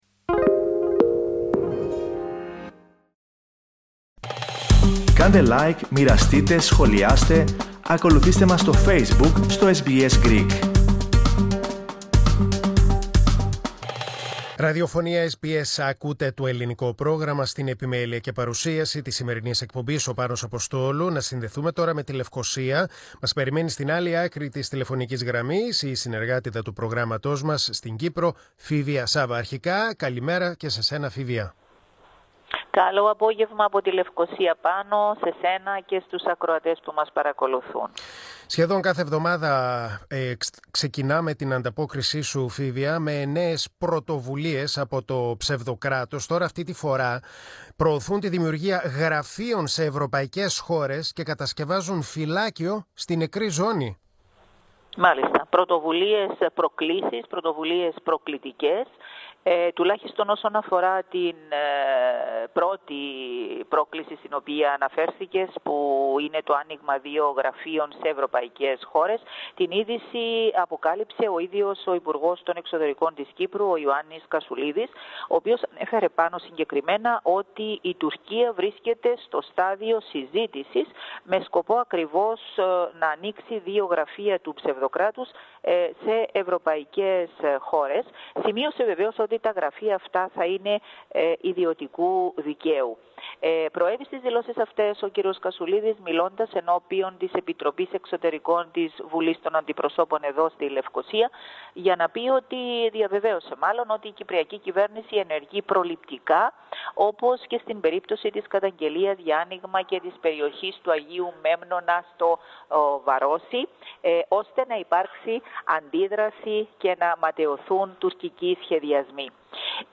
Ακούστε ολόκληρη την ανταπόκριση από την Κύπρο.